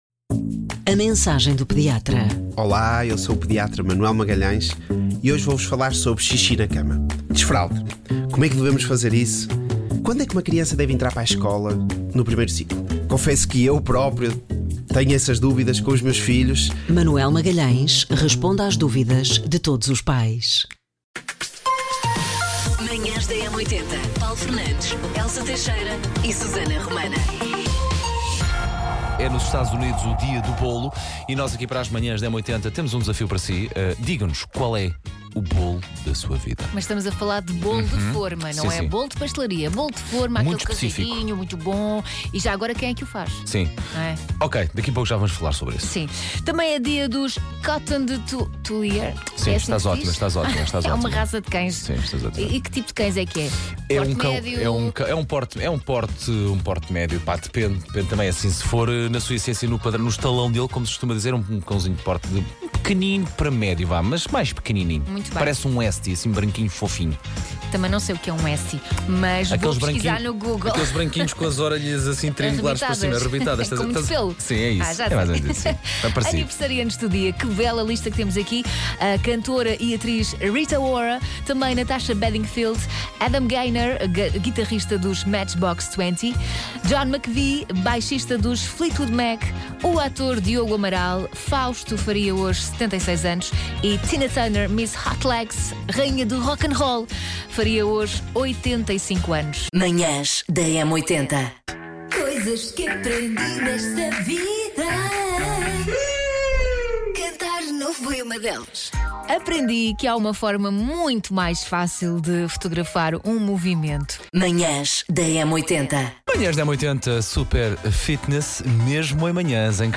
… continue reading 2856 episoder # Conversas # Sociedade # Portugal # Manhãs Da M80